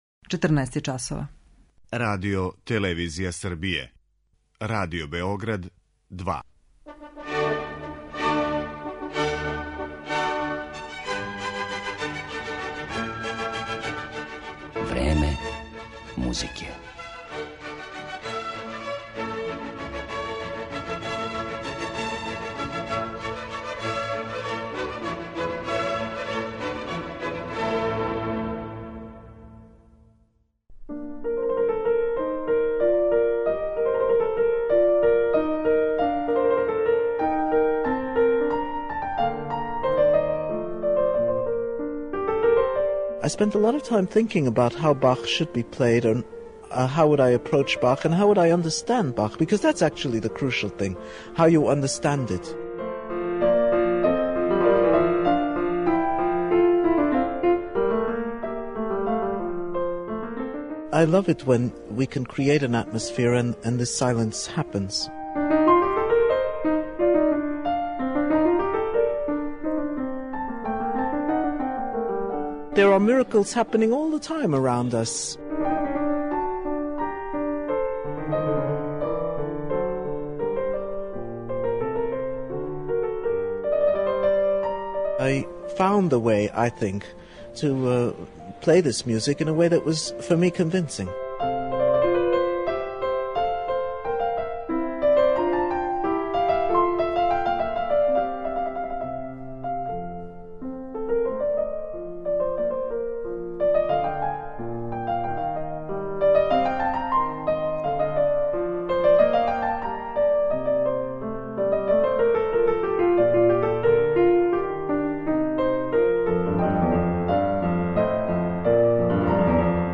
на клавиру